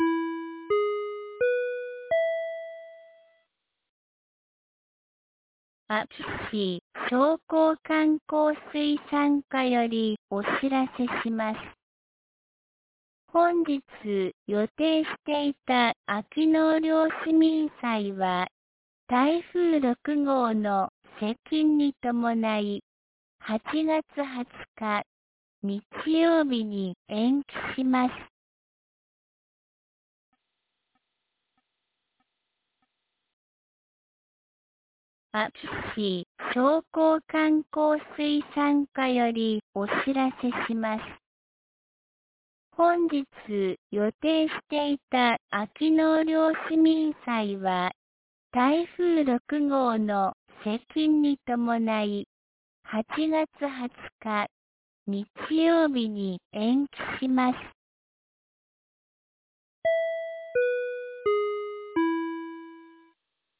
2023年08月06日 09時01分に、安芸市より全地区へ放送がありました。